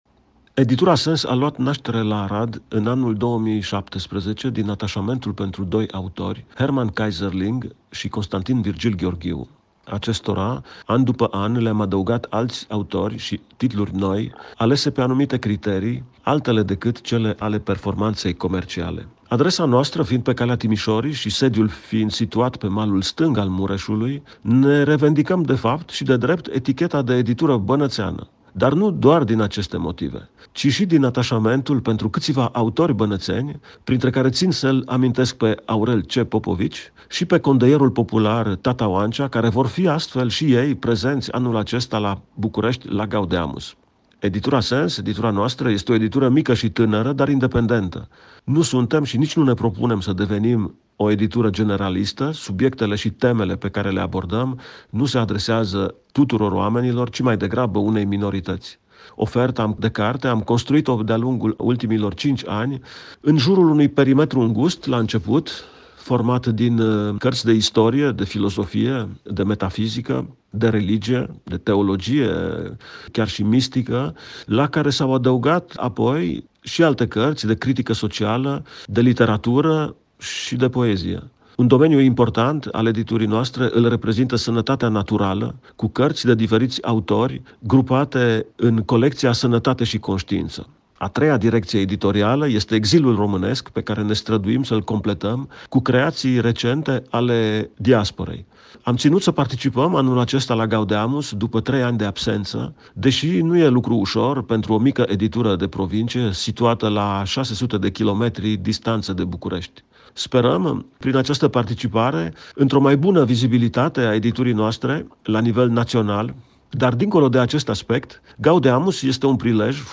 (Interviuri Radio Timișoara, în exclusivitate.)